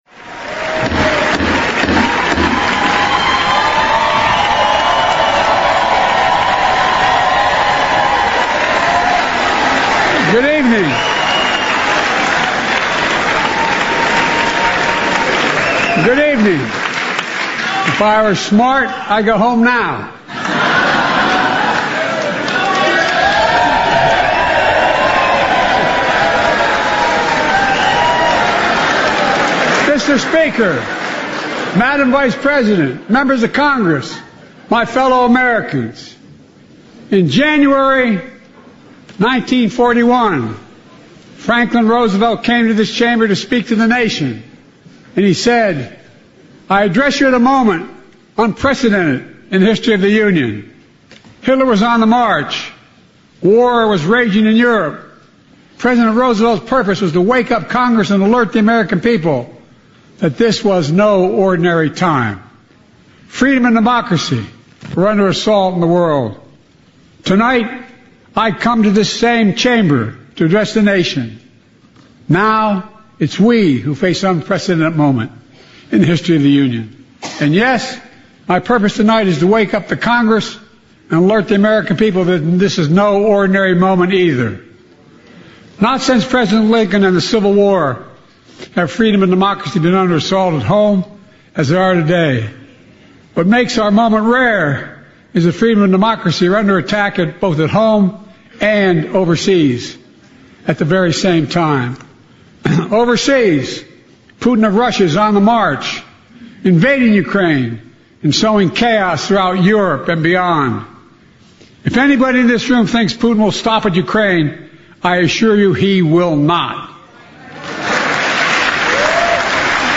拜登总统2024年国情咨文全文